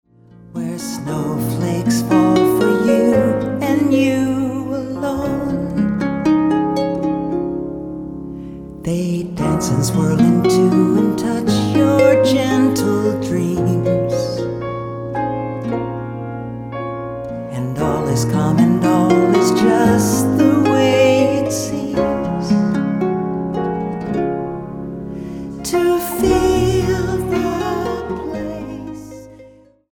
besides various harps